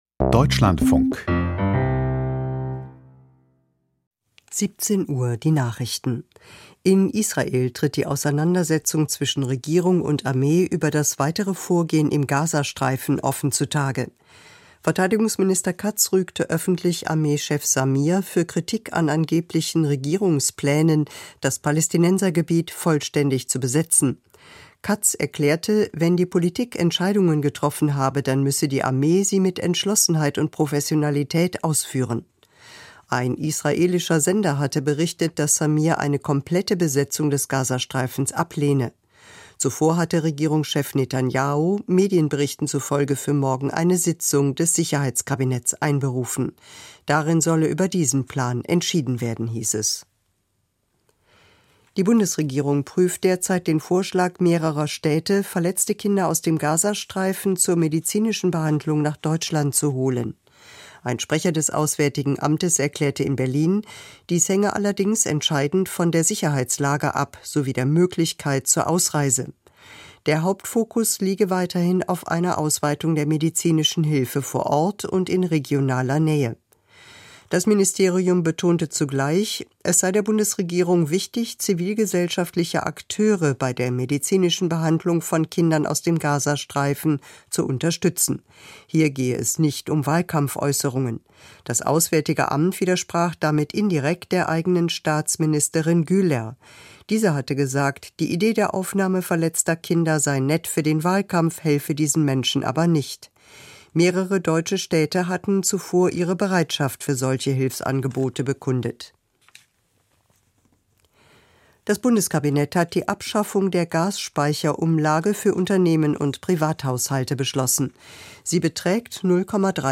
Die Nachrichten vom 06.08.2025, 17:00 Uhr